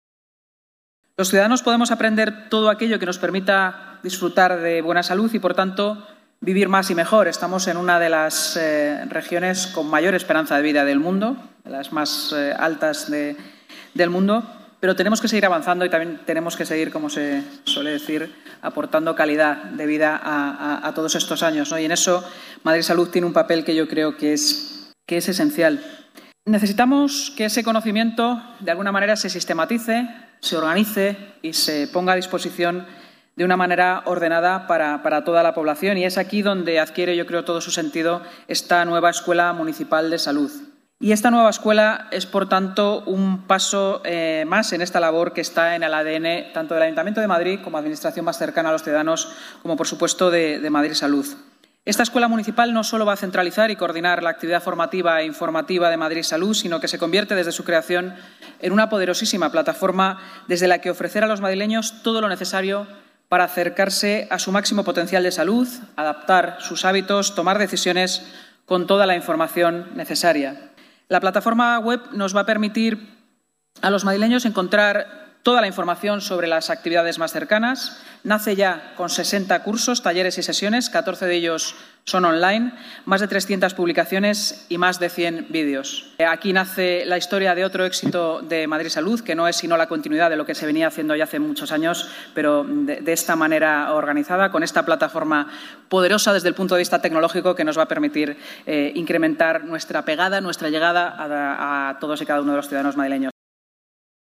La vicealcaldesa de Madrid y delegada de Seguridad y Emergencias, Inma Sanz, ha presentado esta mañana, en el Palacio de Cibeles, la Escuela Municipal de